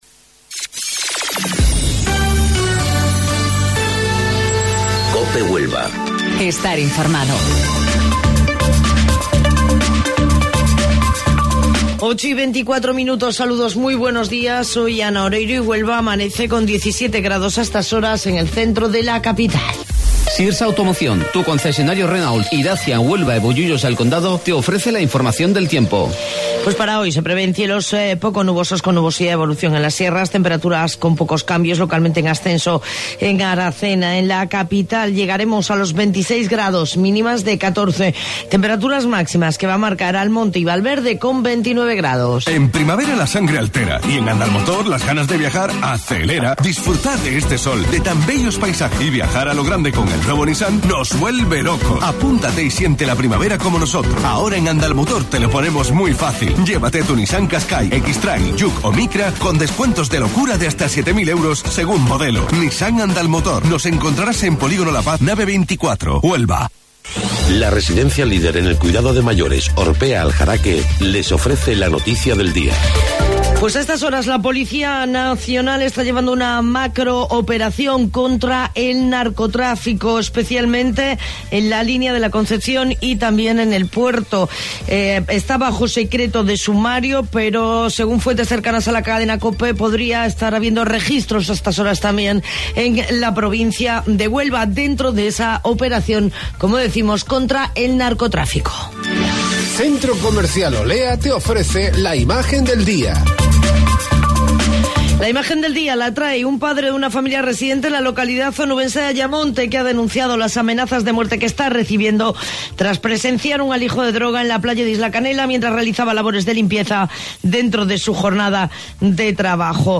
AUDIO: Informativo Local 08:25 del 22 de Mayo